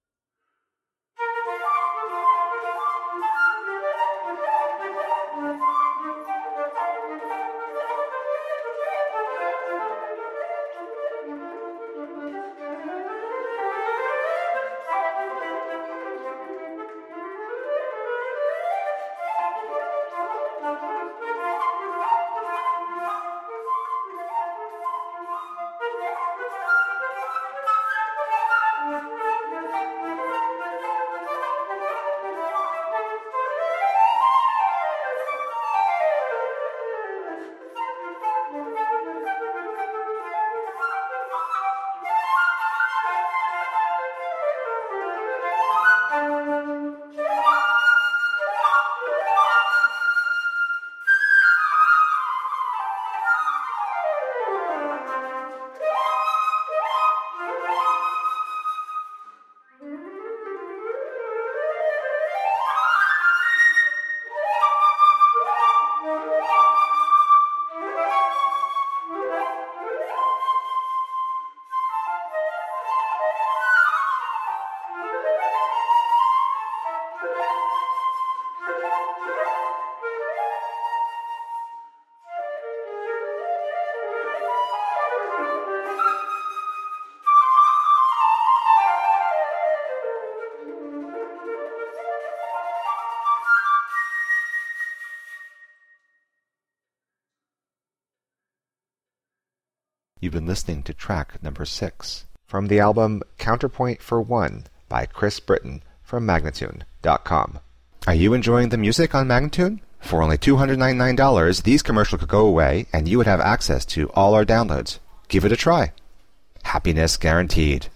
unaccompanied flute
dazzling and virtuosic transcriptions for solo flute